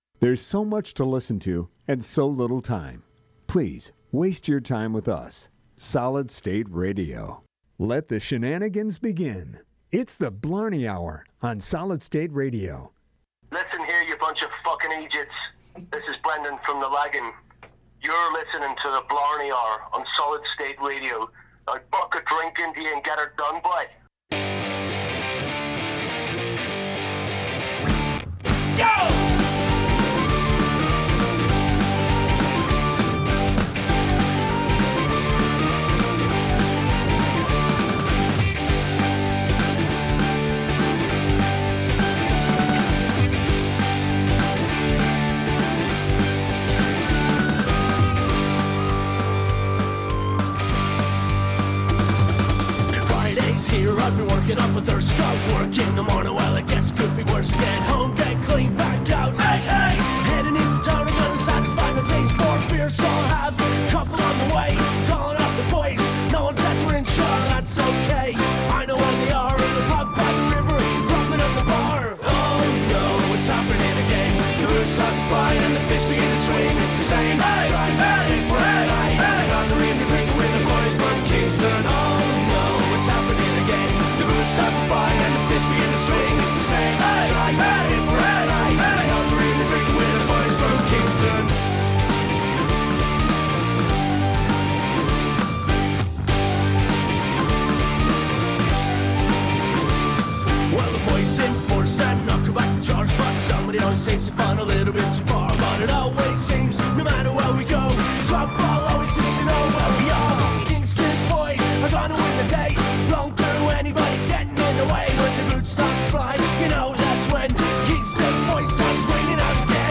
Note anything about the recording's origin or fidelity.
Live from Lapeer Days!